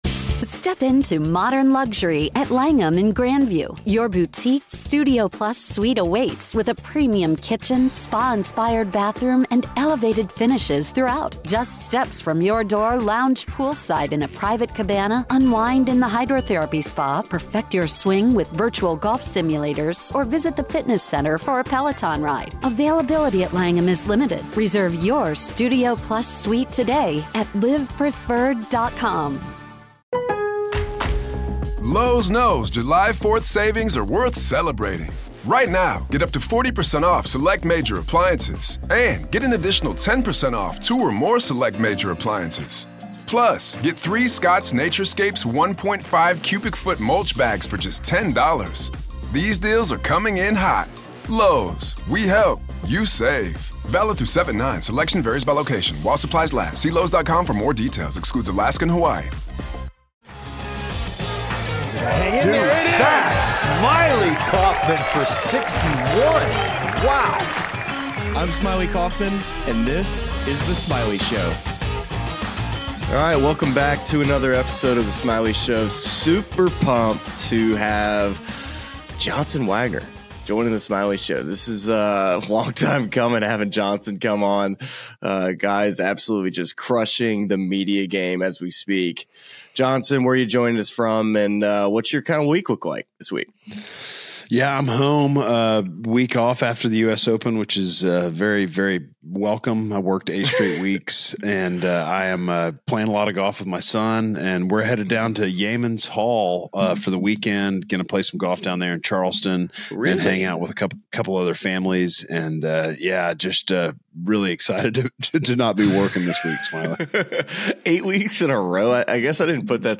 Johnson Wagner Interview: Glory at the U.S. Open, Career Path, State of Pro Golf, and more!